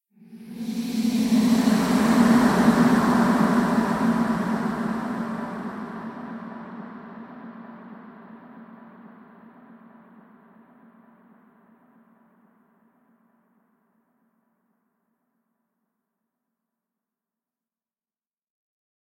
Короткий звук пролетевшего призрака в замке
korotkij_zvuk_proletevshego_prizraka_v_zamke_vuv.mp3